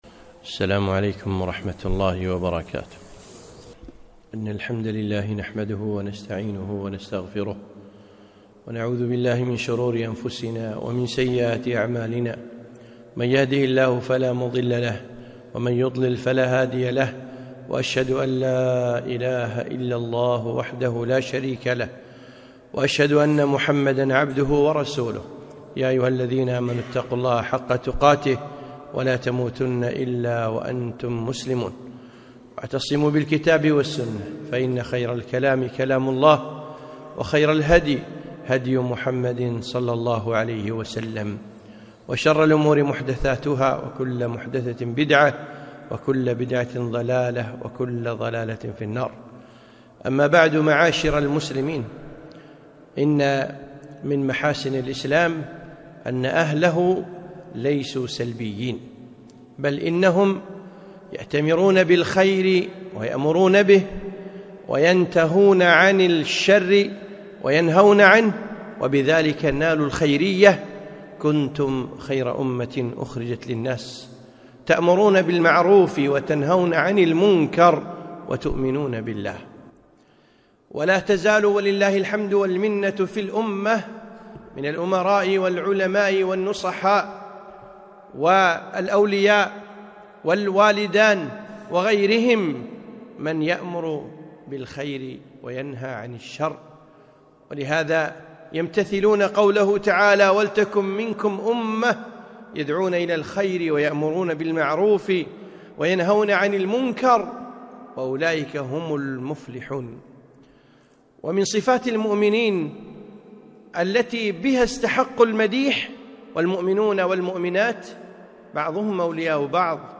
خطبة - كنتم خير أمة أخرجت للناس